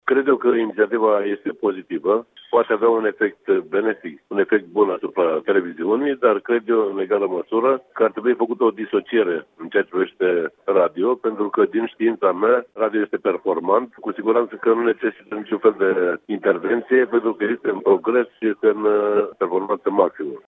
Senatorul UNPR de Iași, Marin Burlea, a vorbit despre performanțele Radioului Public.